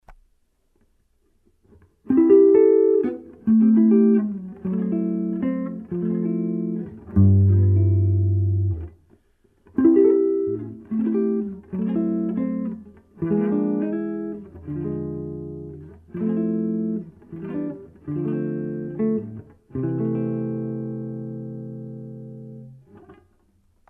Chords
ai_ten2_chords.mp3